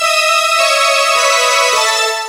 Synth Lick 49-08.wav